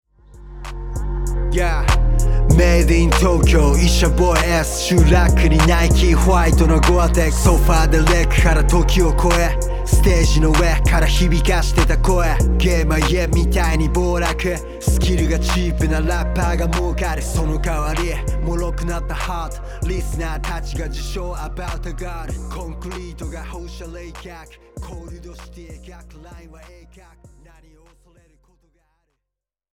トラックメイカー、プロデューサー。